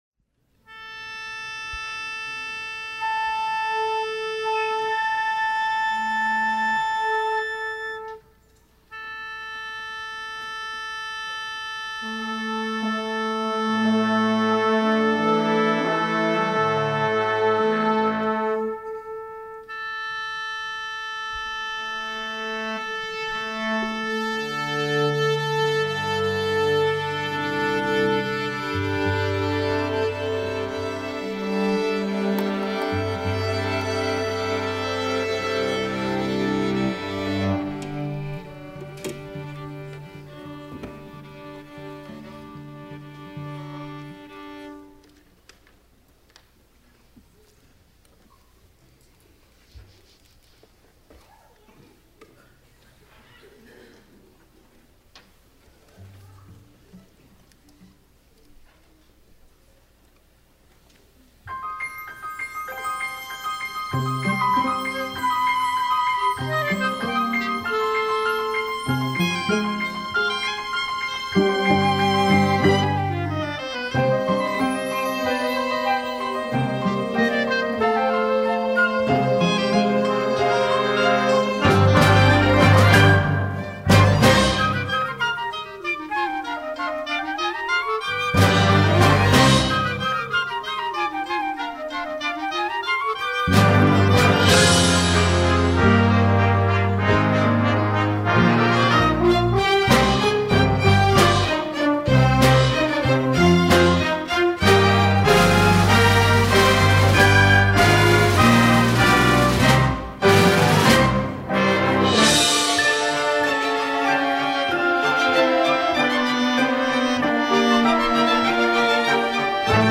Christmas Concert 2015